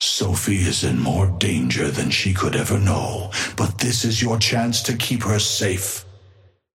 Amber Hand voice line - Sophie is in more danger than she could ever know, but this is your chance to keep her safe.
Patron_male_ally_tengu_start_03.mp3